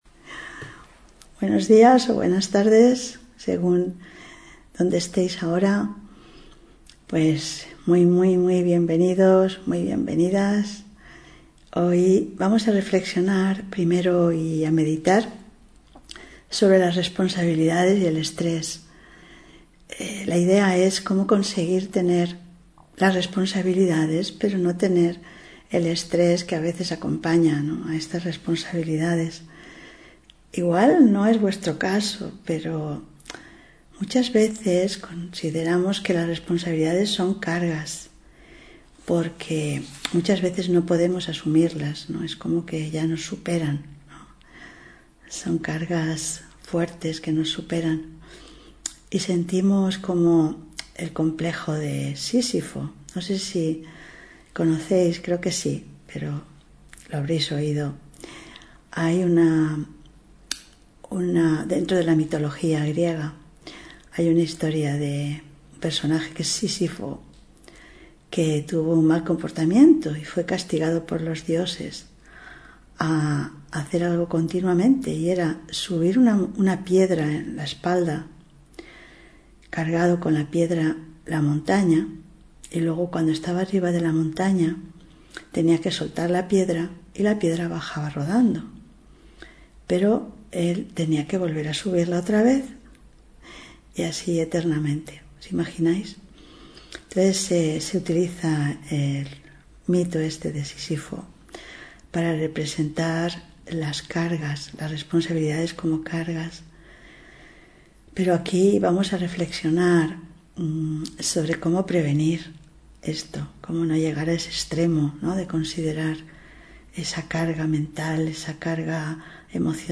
Meditación y conferencia: Responsabilidad sin estrés (20 Abril 2024)